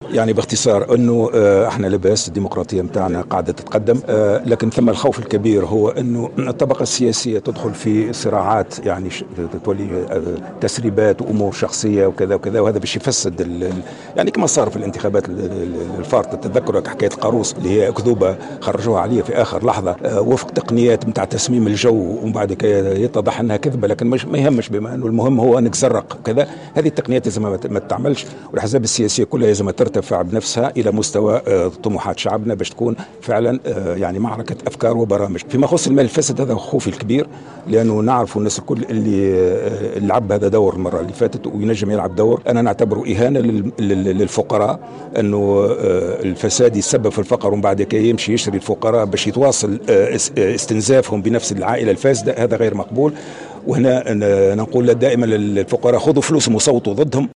قال المترشح للانتخابات الرئاسية السابقة لأوانها عن حزب الحراك، محمد المنصف المرزوقي، خلال ندوة صحفية عقدها صباح اليوم الأربعاء بالعاصمة، إن أقوى منافس له في هذه الانتخابات سيكون "المال الفاسد" وإن على التونسيين أن يتجندوا ضد هذا الخطر، مشددا على دور الإعلام في كشف ما أسماها "العصابات" المورطة في استغلاله.